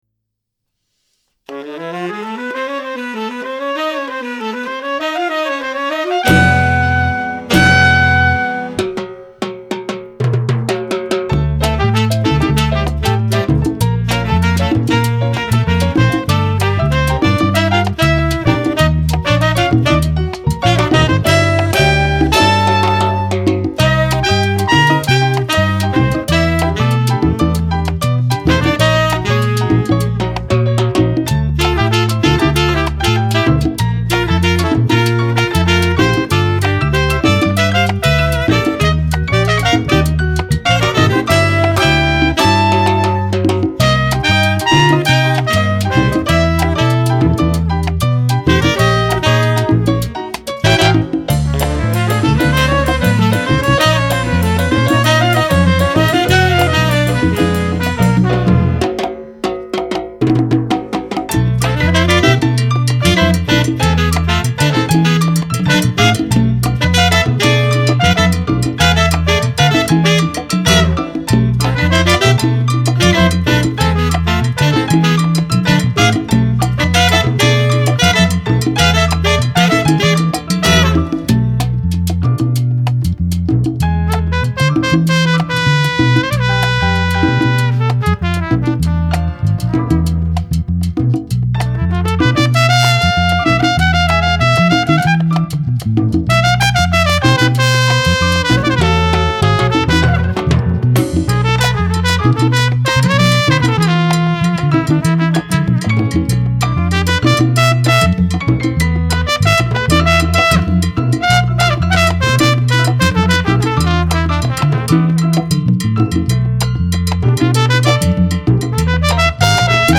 Enregistré au Studio Gam à Waimes (Belgique)